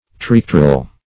triquetral - definition of triquetral - synonyms, pronunciation, spelling from Free Dictionary
Triquetral \Tri*que"tral\, a.